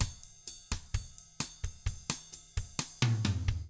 129BOSSAF4-R.wav